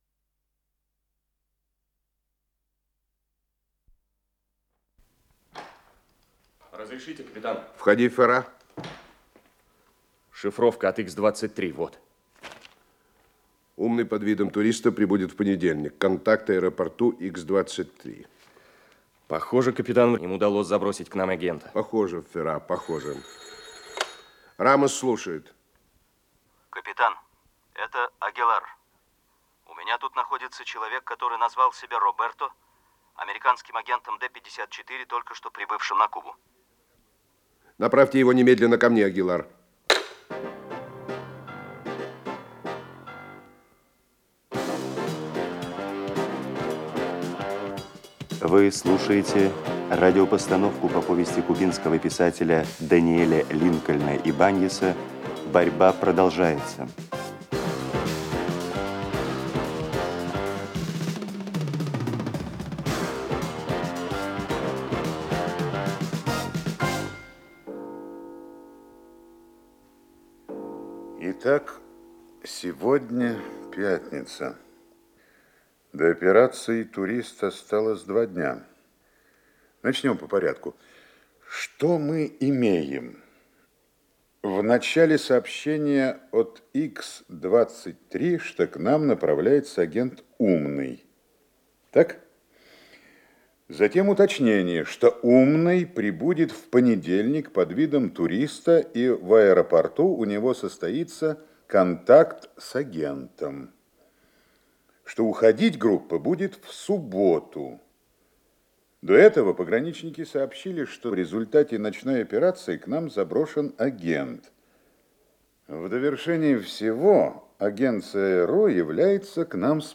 Радиопостановка (речь идёт о борьбе органов кубинской гобезопасности с агентами ЦРУ